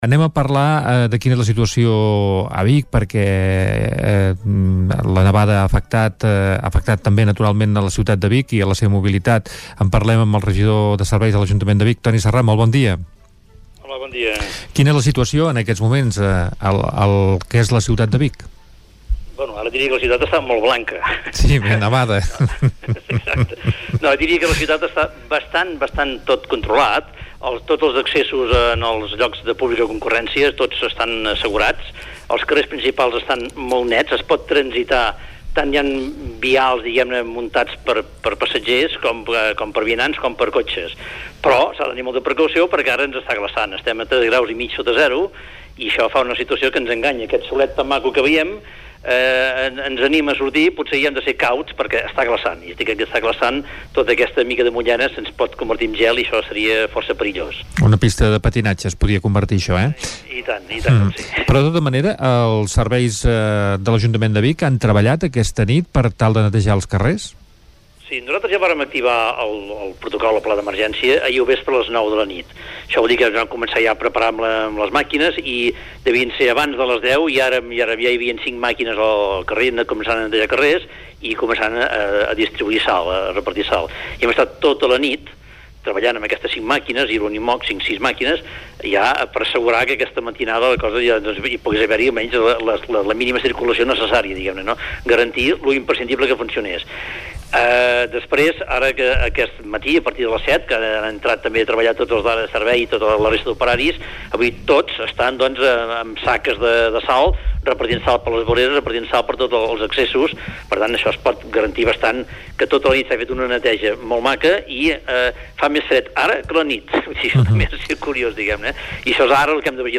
Entrevista a Antoni Serrat a l’Hora de la Veritat a les 9.30 del matí
Entrevista-a-Antoni-Serrat.mp3